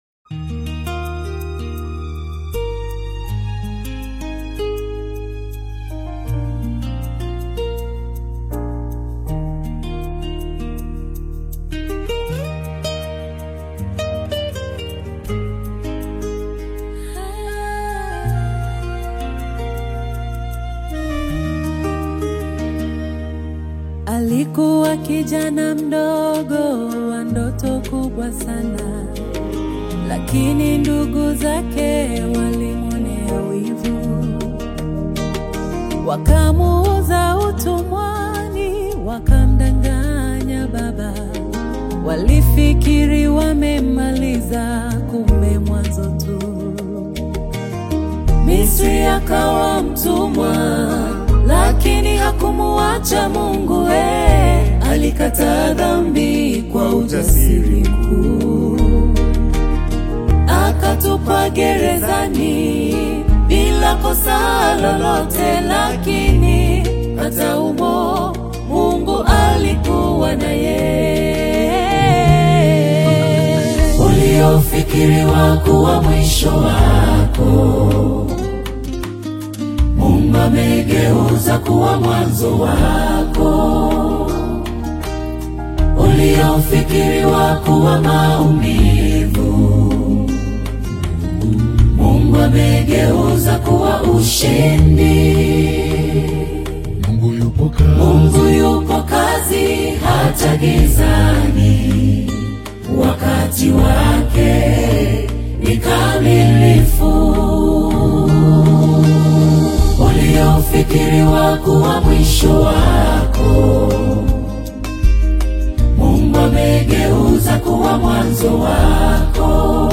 spiritually uplifting gospel single
Genre: Gospel